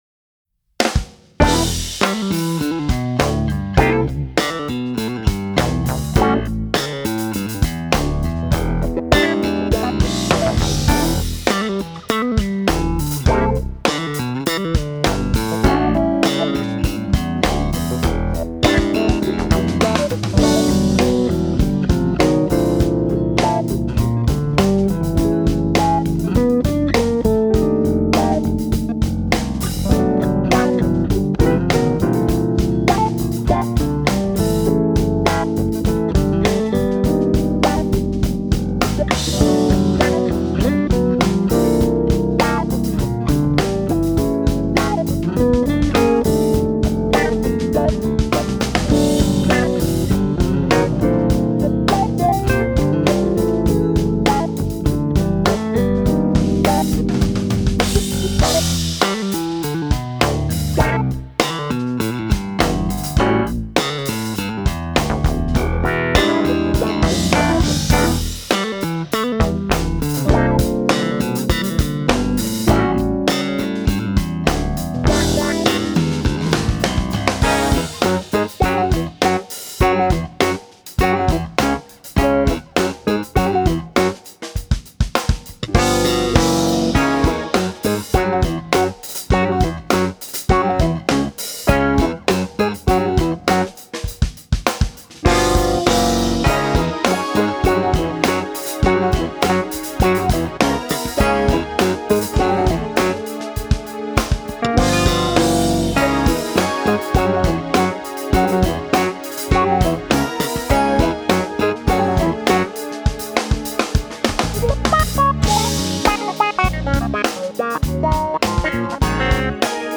basgitarista